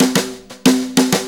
Brushes Fill 69-01.wav